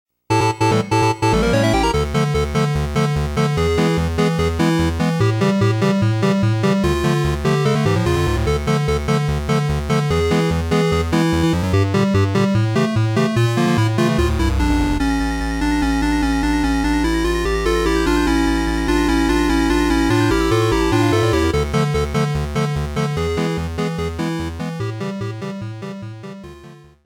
といっても音色変えただけなんで、ぜんぜん似てないと思われますが・・・
どちらかと言うと、ファミコンチック。
PCエンジンバージョン